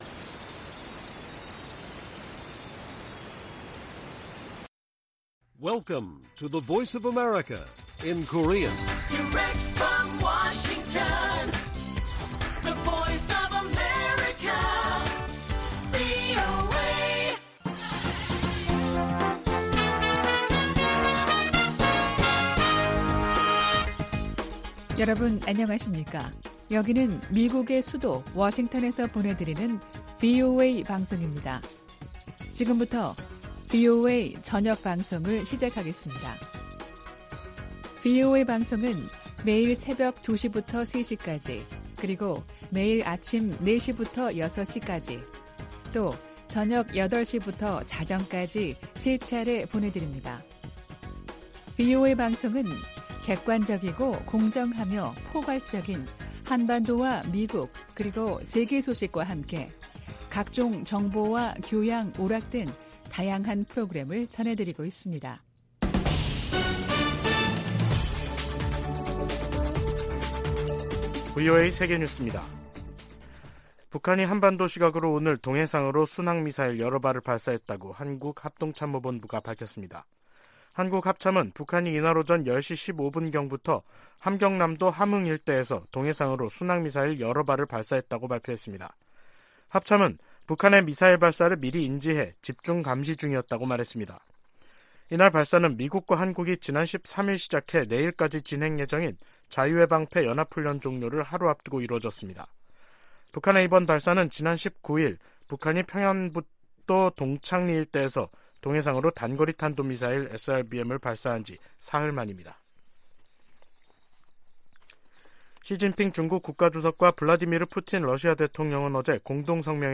VOA 한국어 간판 뉴스 프로그램 '뉴스 투데이', 2023년 3월 22일 1부 방송입니다. 북한이 또 다시 순항미사일 여러 발을 동해상으로 발사했습니다. 미국 정부는 모의 전술핵 실험에 성공했다는 북한의 주장에 우려를 표했습니다. 미국 국방부가 북한의 미사일 프로그램이 제기하는 도전을 면밀히 감시하고 있다고 강조했습니다.